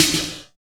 50.09 SNR.wav